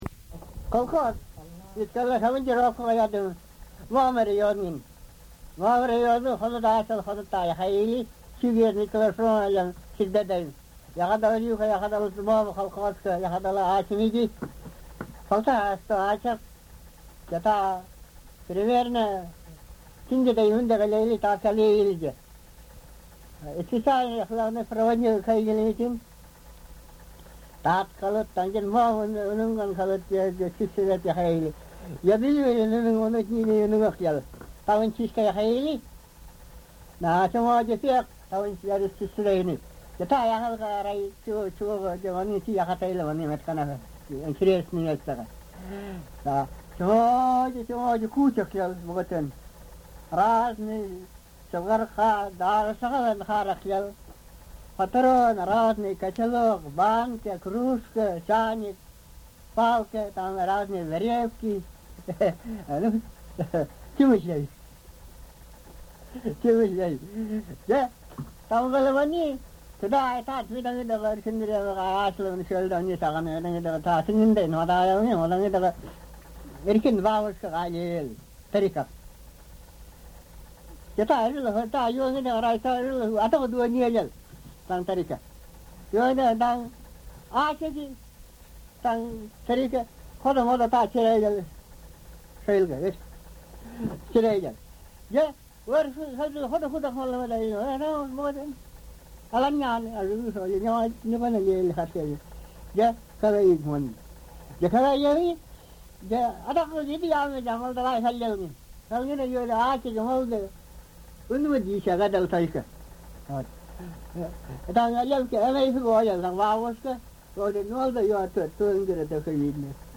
Online Kolyma Yukaghir Documentation